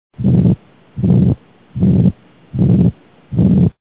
Südametoonide helisalvestused (H.)
Vatsakeste vaheseina puudulikkus